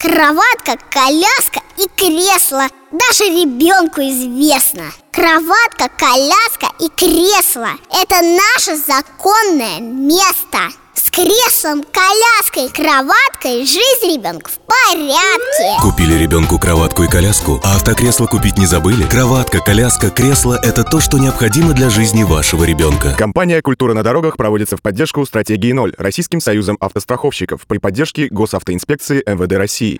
трц-46.-Новогоднее-стихотворение-30-сек.mp3